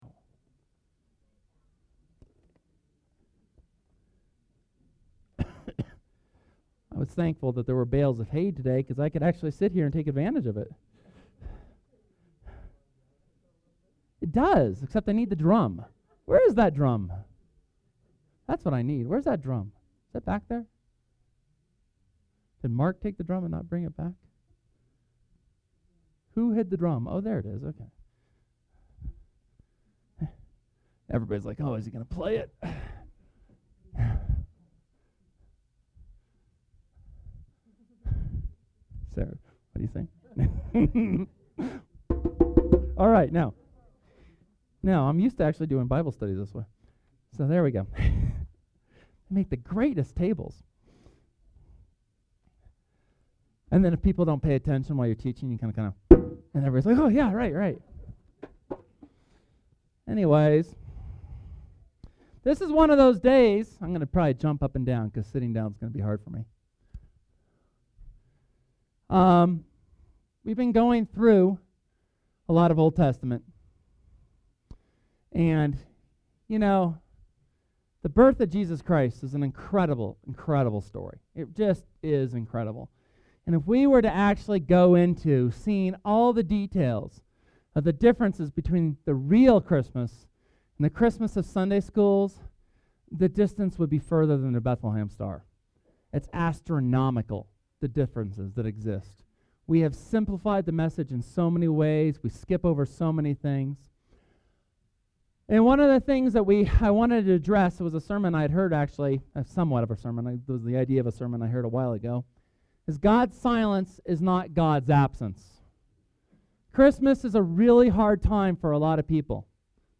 Church of the Resurrection sermon from December 23, 2012 on the 400 years of silence between Malachi and Mark, but some of the most tumultuous years world history has seen, all "just" in time for the birth of the Christ.